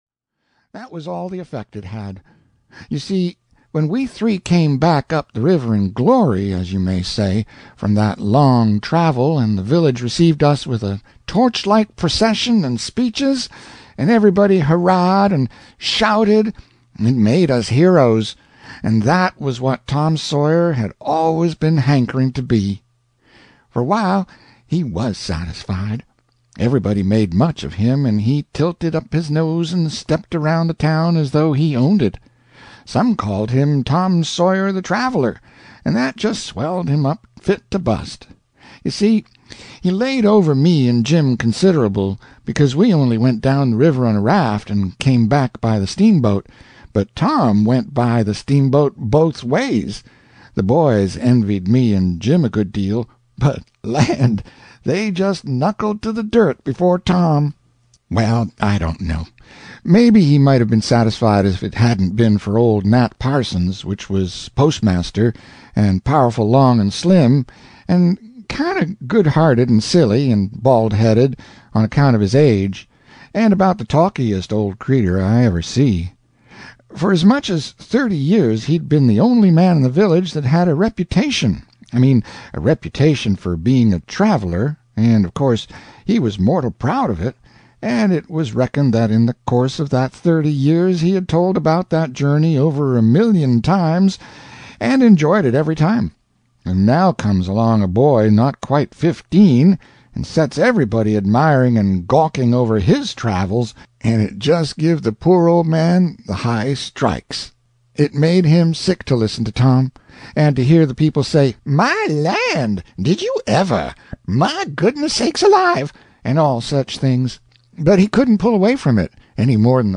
Tom Sawyer Abroad (EN) audiokniha
Ukázka z knihy